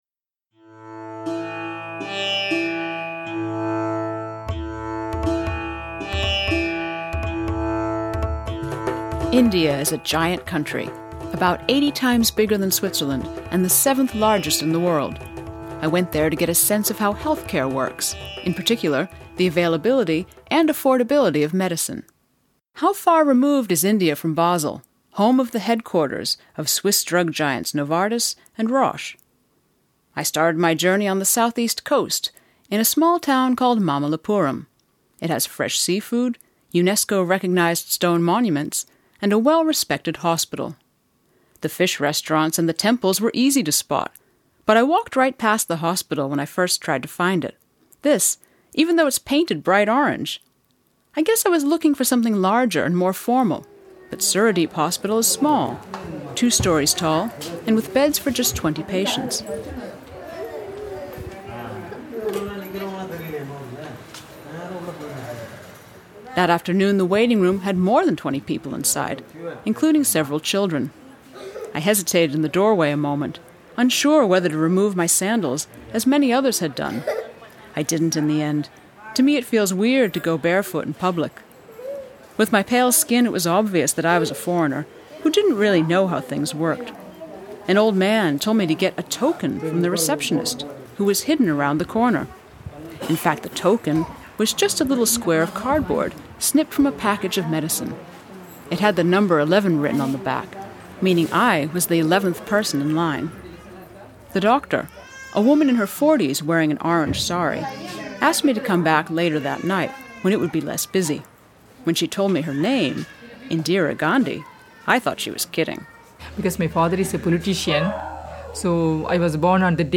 Along the way she meets doctors, pharmacists and patients.